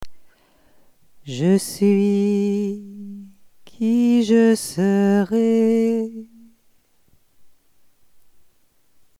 Il me ferait plaisir d'en être avisée si vous utilisez un des textes du site ou une des perles bibliques chantées en récitatif.